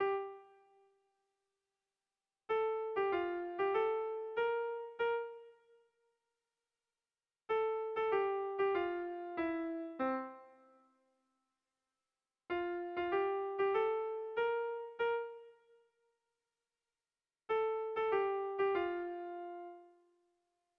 Gabonetakoa
Lauko txikia (hg) / Bi puntuko txikia (ip)
AB